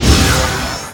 Magic_SpellImpact03.wav